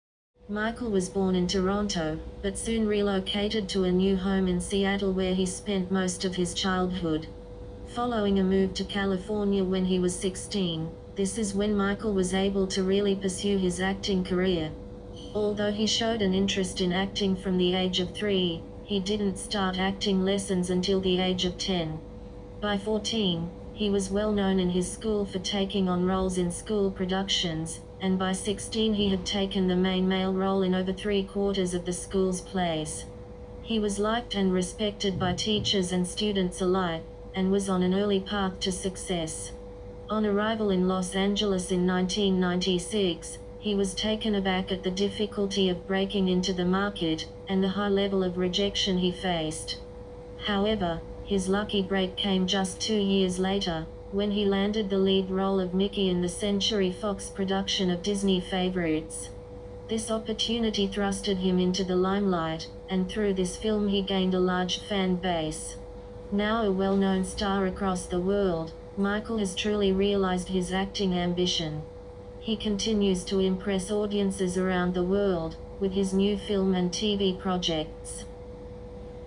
Listening Practice 8: Multiple Choice (Australian Accent) - EnglishVista | Free IELTS Test Prep | Learn English